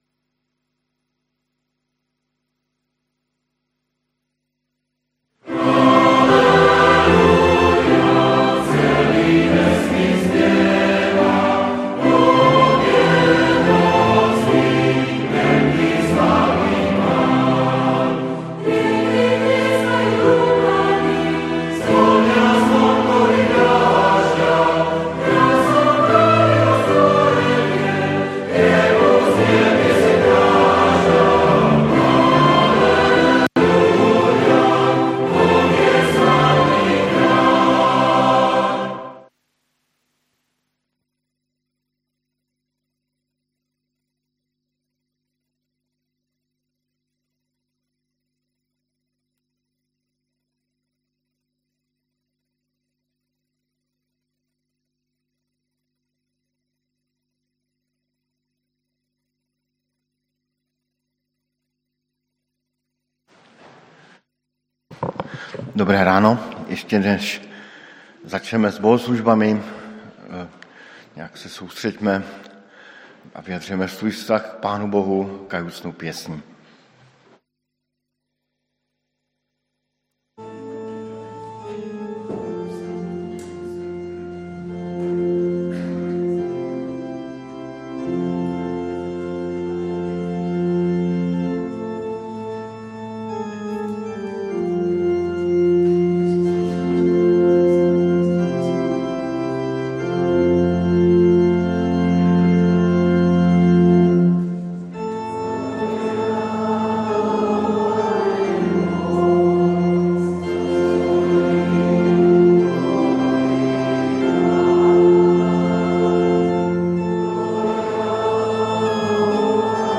Stránka zboru Cirkvi bratskej v Bratislave - Cukrová 4
21:3-5,10-11 Podrobnosti Kázeň Prehliadač nepodporuje prehrávač.